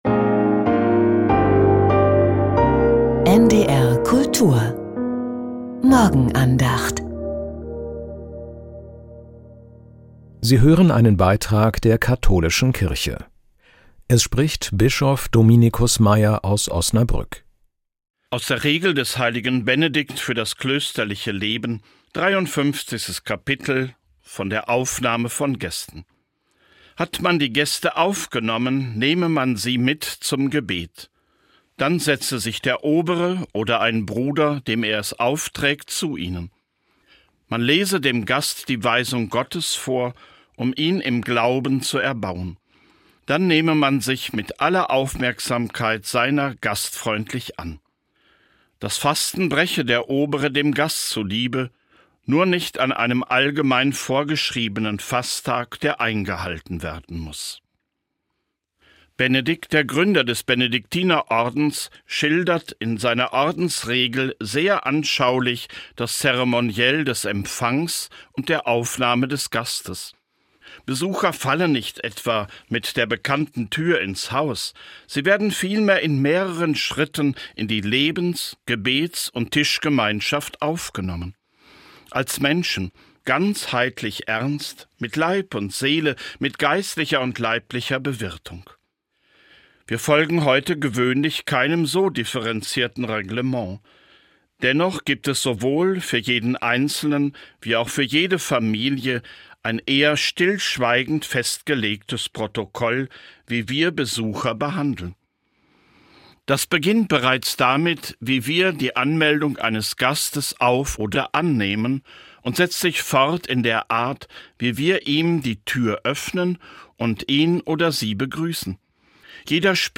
Jeder Gast ist eine Botschaft an uns: Die Morgenandacht mit Bischof